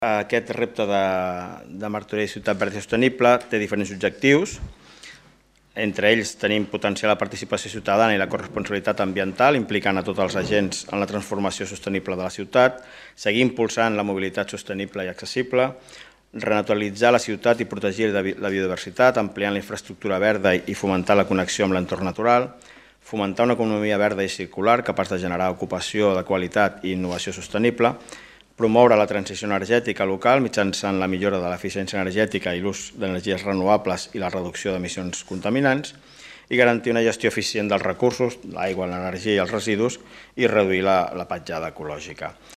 Albert Fernández, regidor d'Agenda Urbana i Planificació Urbanística